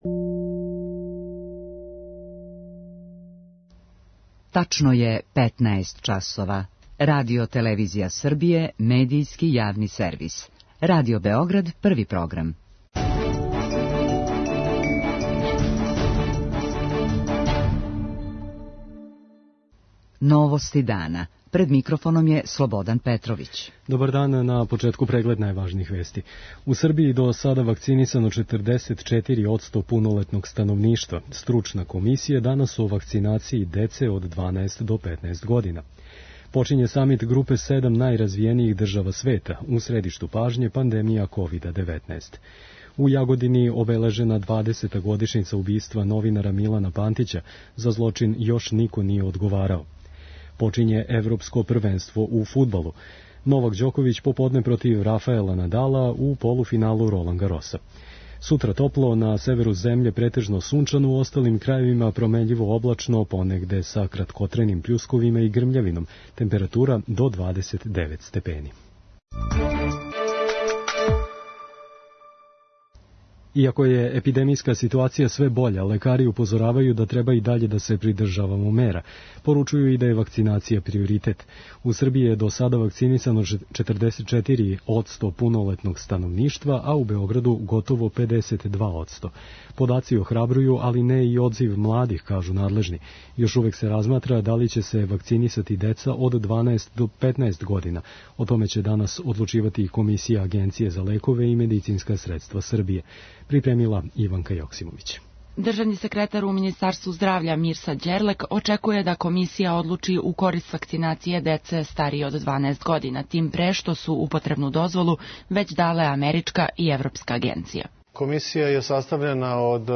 До сада је вакцинисано 15,64 одсто младих од 18 до 30 година - највише у Нишу, Новом Саду, Београду, а најмање у Тутину. преузми : 7.06 MB Новости дана Autor: Радио Београд 1 “Новости дана”, централна информативна емисија Првог програма Радио Београда емитује се од јесени 1958. године.